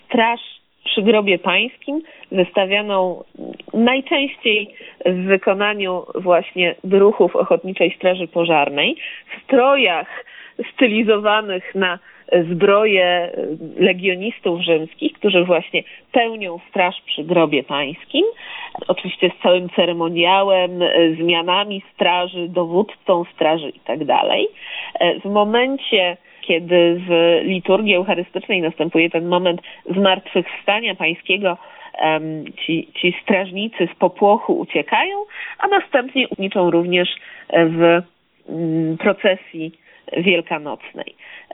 O tradycji opowiada